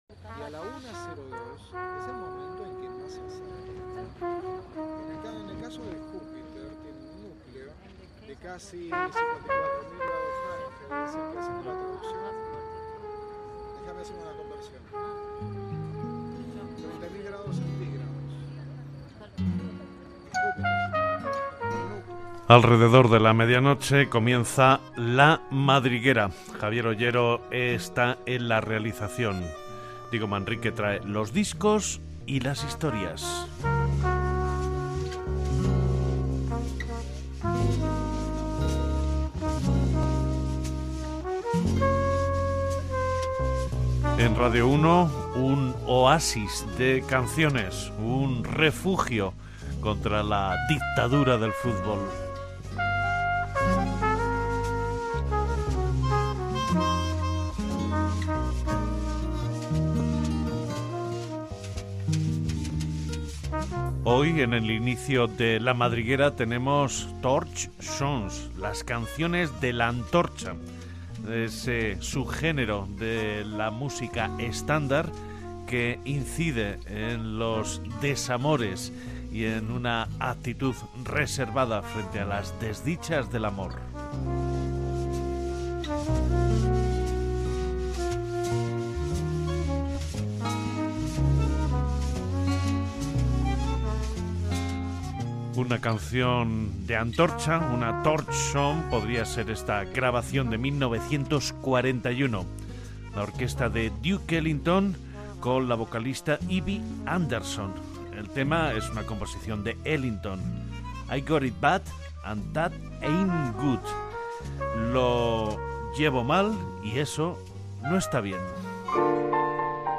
Equip, presentació de l'espai dedicat a les "Torch songs" i tema musical
Musical